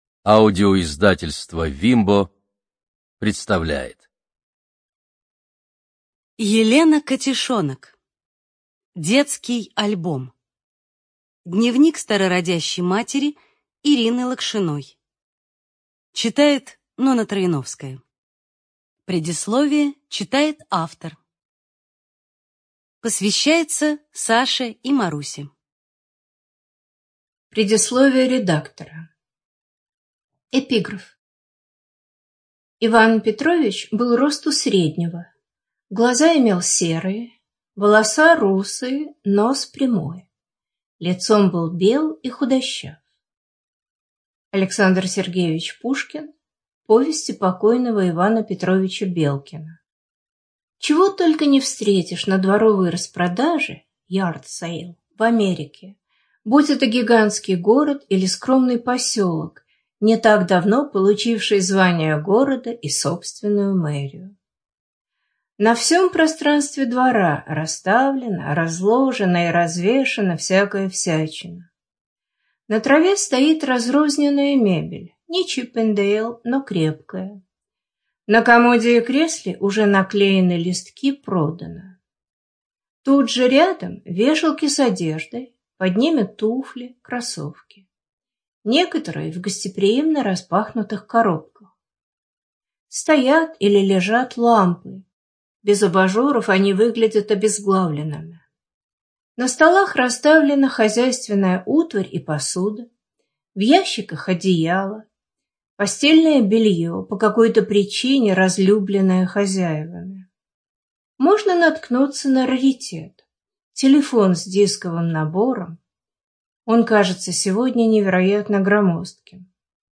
Студия звукозаписивимбо